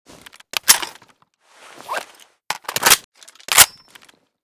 fal_reload_empty.ogg